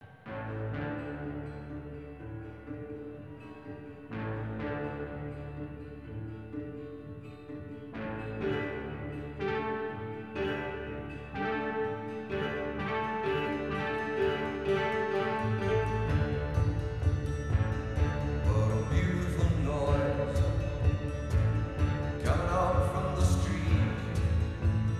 Impulse Reverb
3 short 24 bit stereo example wav files